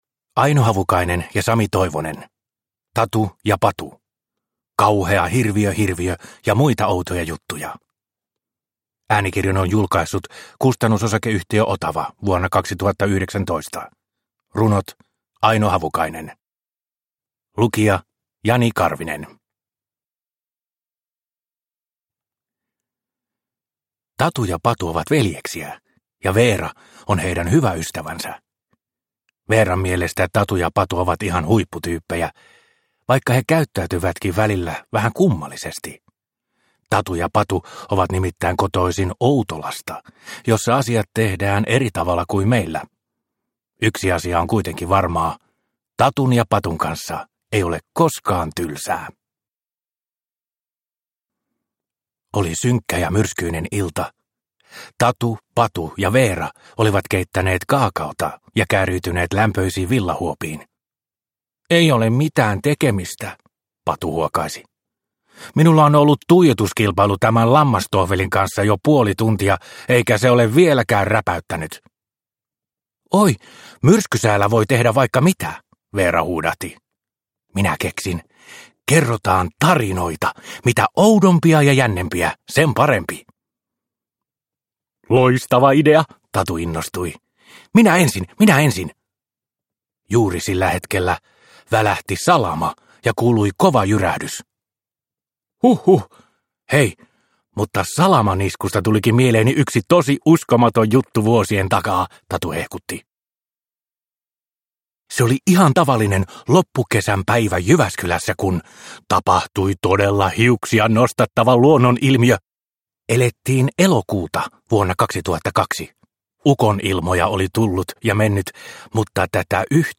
Tatu ja Patu, kauhea Hirviö-hirviö ja muita outoja juttuja – Ljudbok – Laddas ner